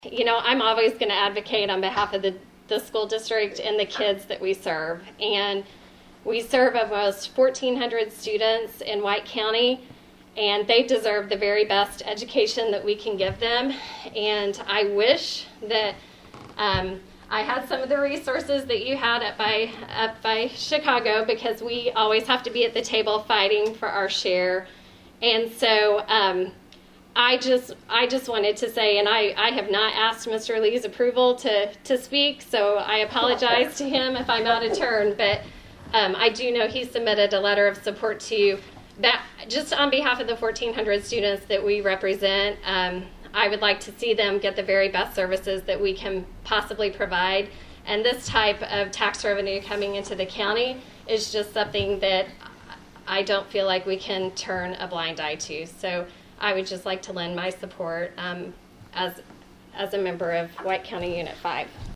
Another White County Board Public Hearing; Another Lively Discussion